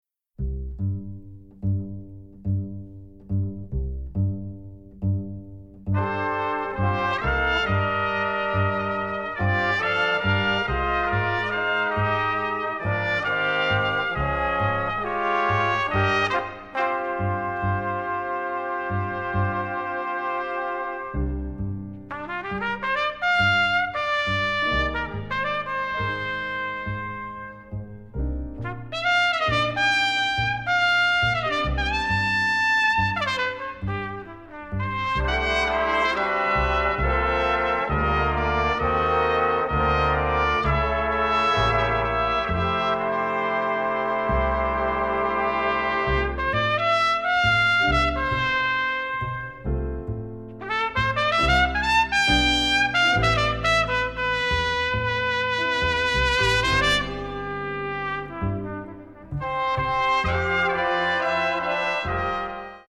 Big Band Charts
Solos: trumpet
Instrumentation: 3 bones, 4 trumpets, piano, bass, drums